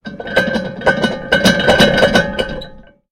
Громкий звук промышленного вентилятора в цеху